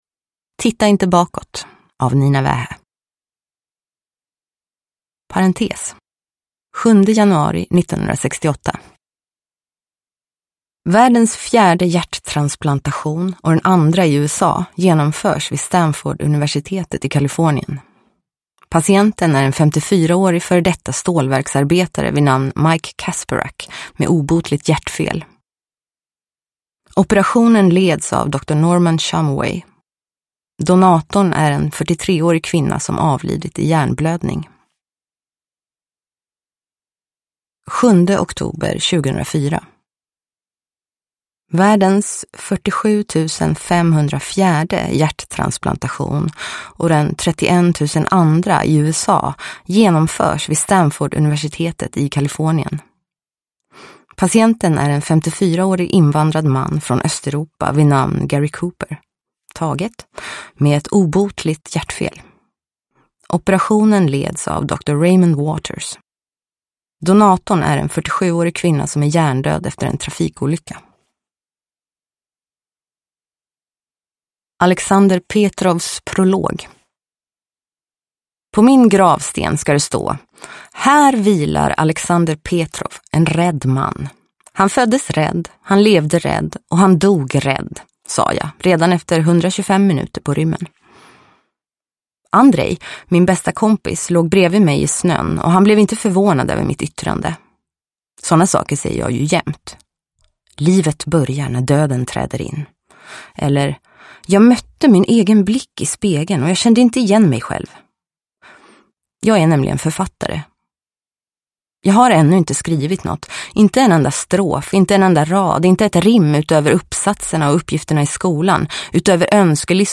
Titta inte bakåt! – Ljudbok – Laddas ner
Uppläsare: Nina Wähä